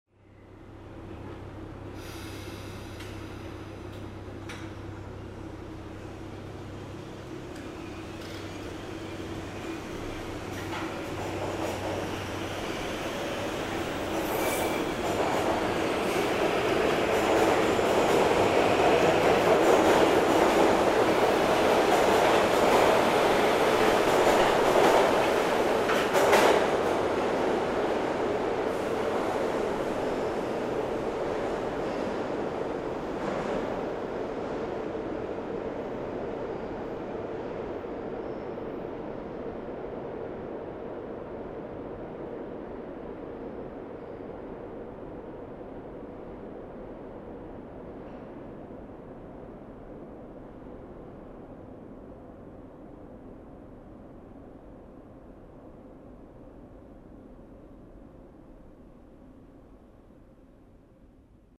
Поезд метро отходит от станции